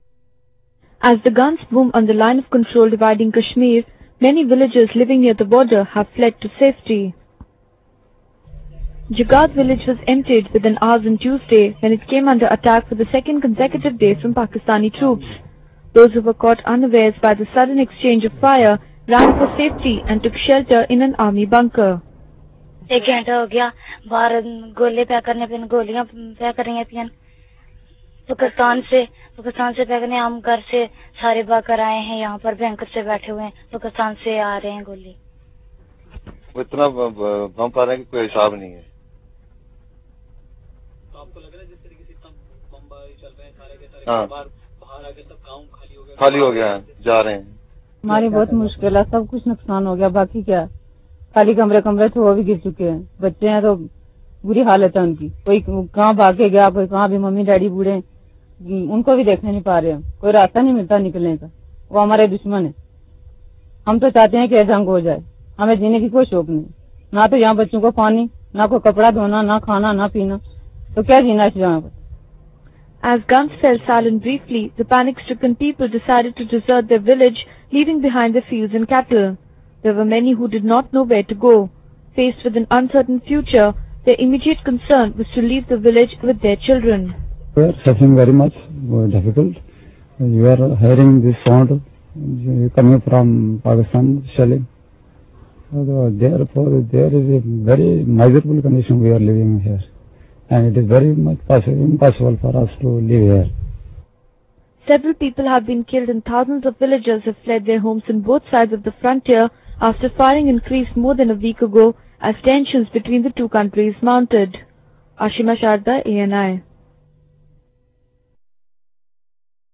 In video: A village in the Rajouri sector of Jammu empties within hours following heavy shelling by Pakistani troops.